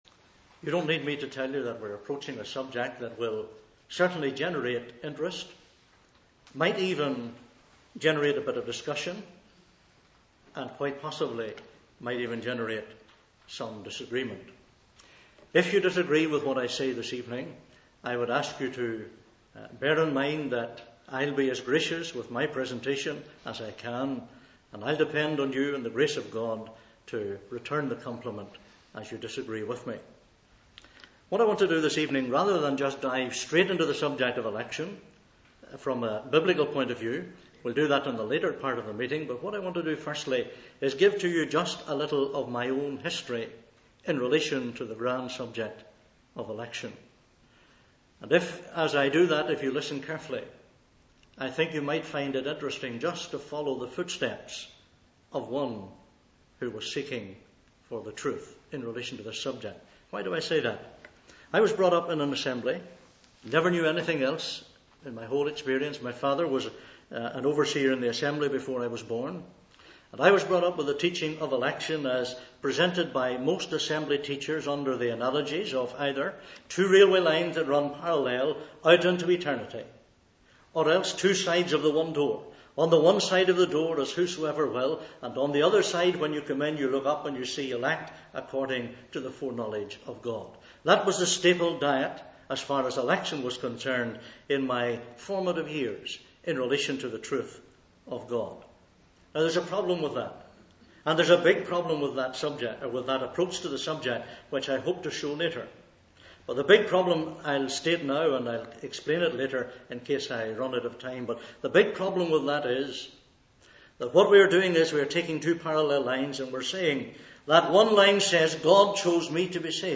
He pays particular attention to Ephesians 1:4 where the saved are said to have been “chosen in Christ” (Message preached 10th May 2007)